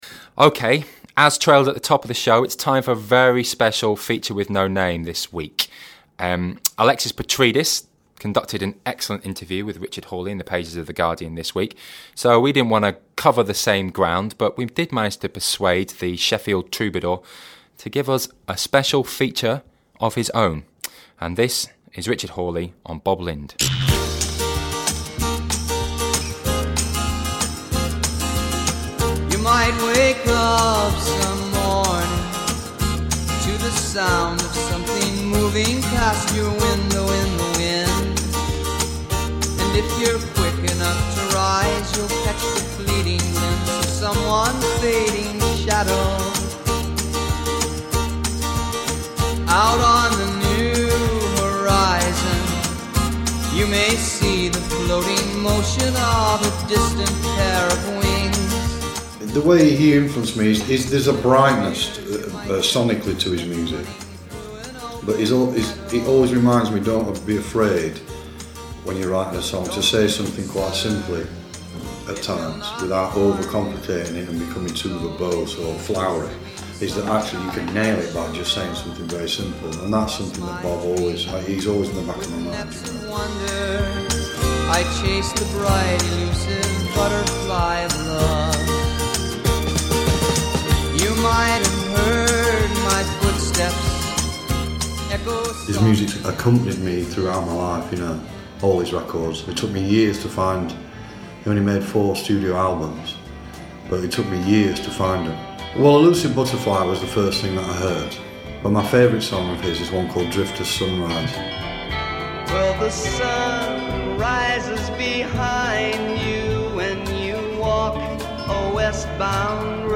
Richard Hawley on Bob Lind, intvw with the Guardian's Music Weekly